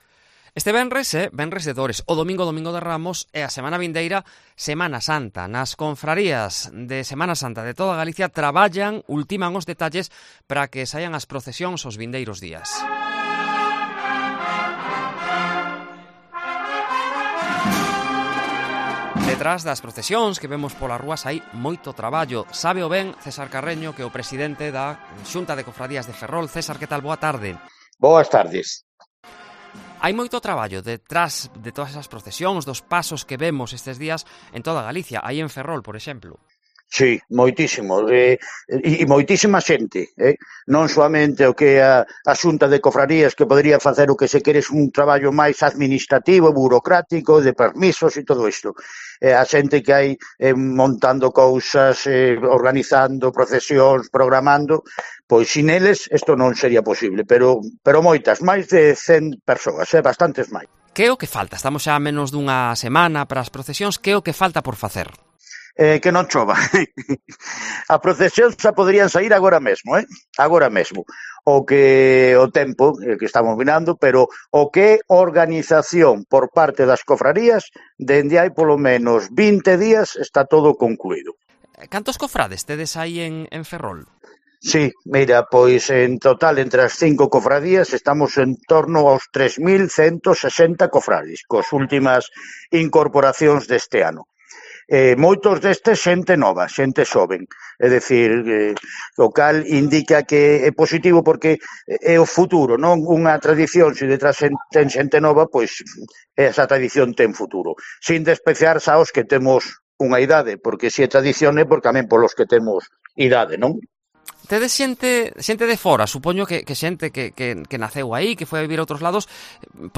Herrera en Cope Galicia